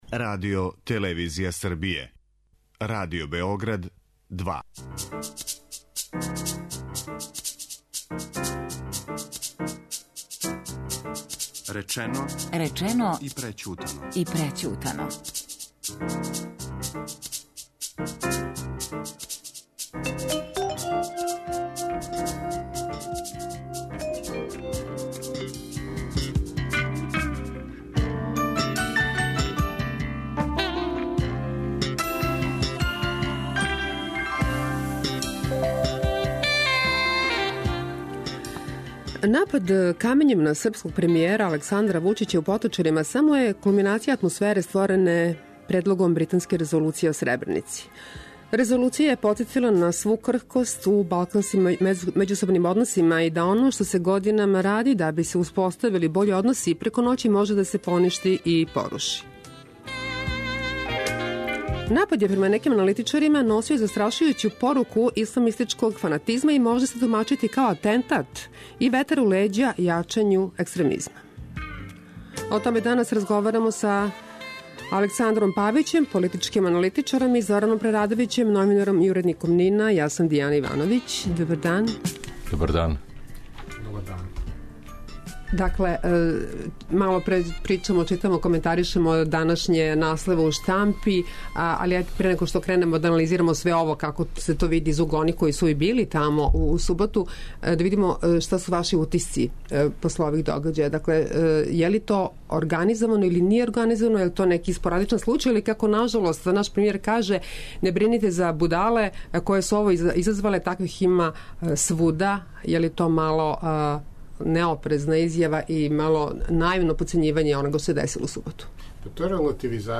Напад је према неким историчарима носио застрашујућу поруку исламистичког фанатизма и може се тумачити као атентат и ветар у леђа јачању ектремизма. О томе данас разговарамо са